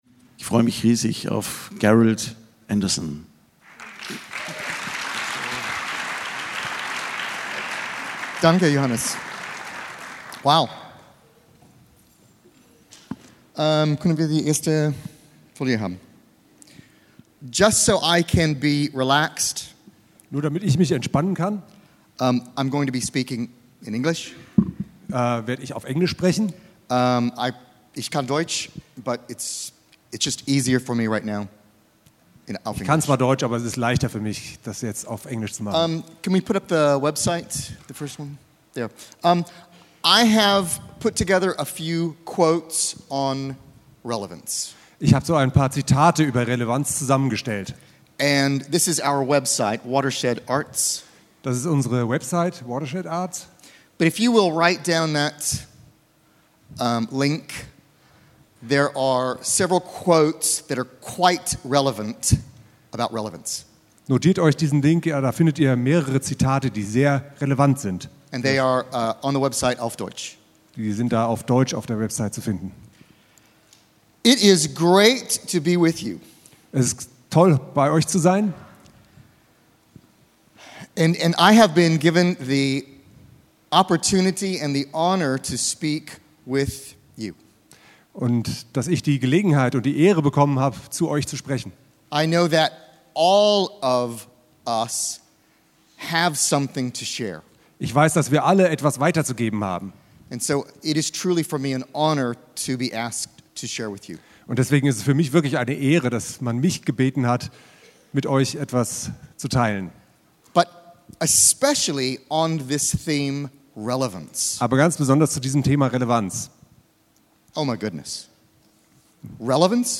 Wie sie dazu kam, was sie an Brücken fasziniert und wie sie über himmlische Brücken denkt, erzählt sie uns in dieser upgRADe-Folge. Das Gespräch